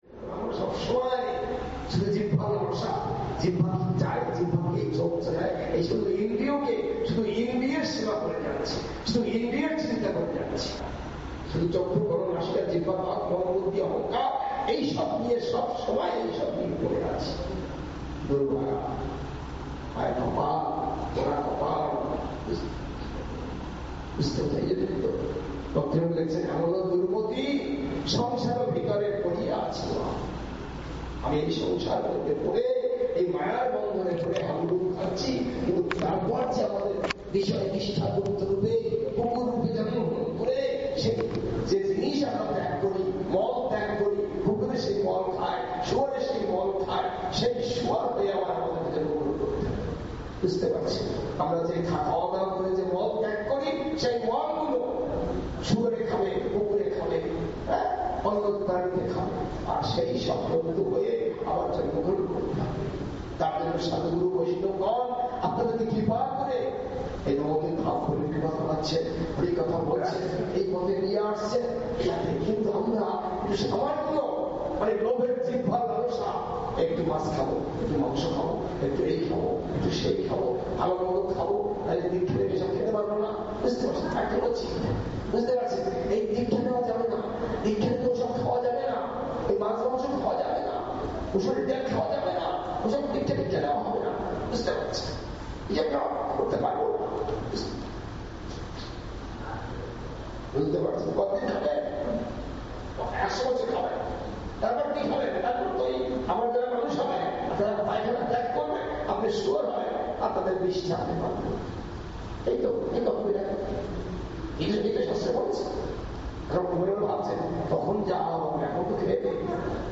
evening class 15 March 2019